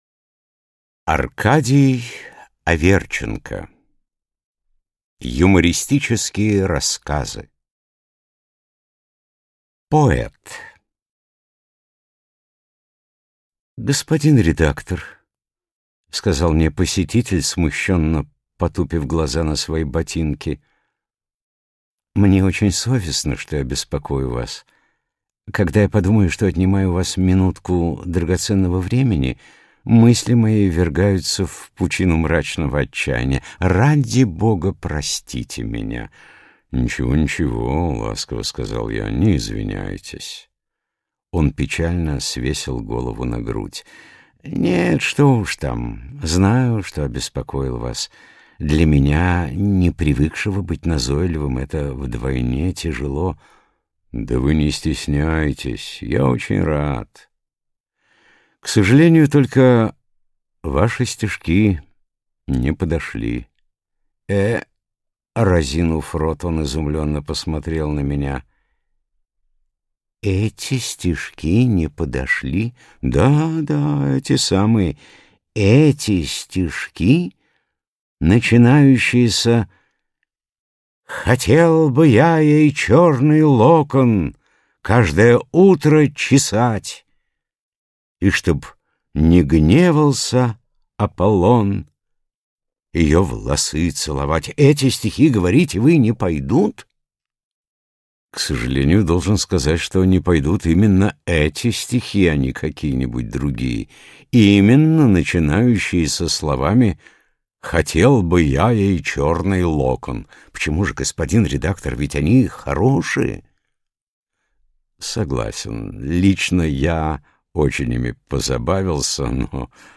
Аудиокнига Серебряный век русского юмора | Библиотека аудиокниг